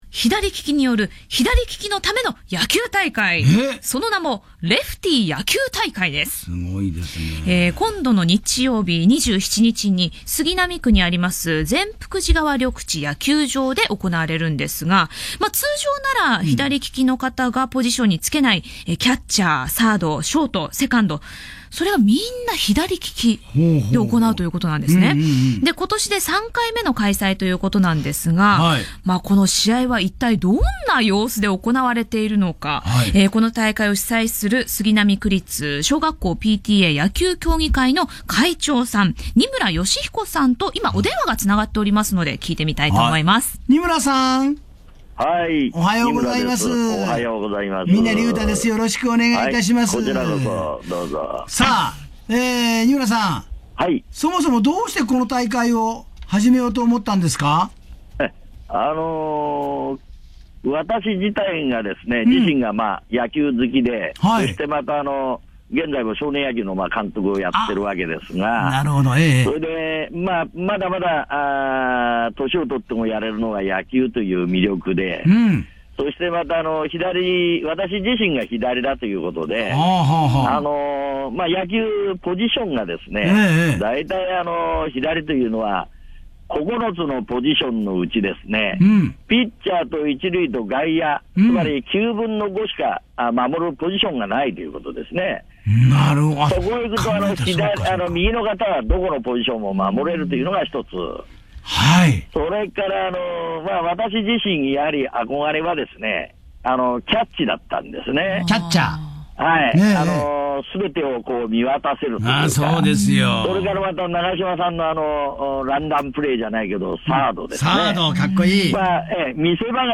「レフティ野球」電話インタービュー出演